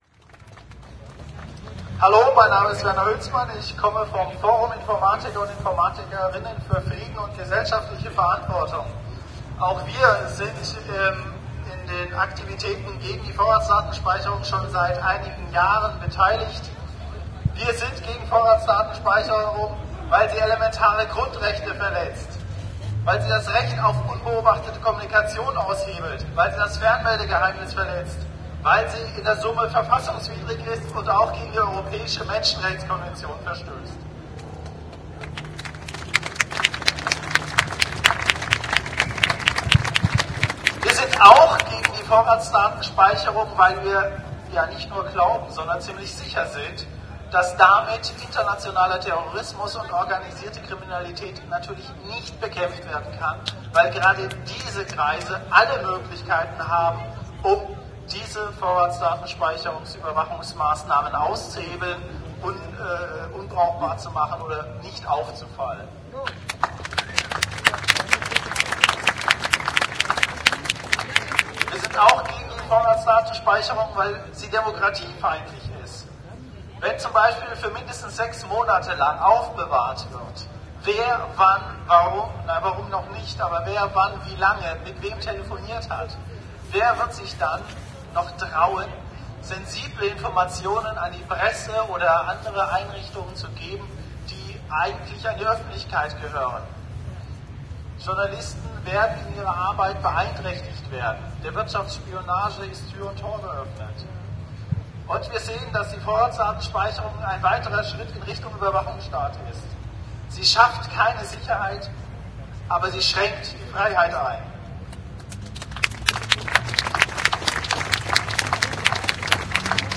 Demonstration gegen Sicherheits- und Überwachungswahn am 20. Oktober 2006 in Bielefeld.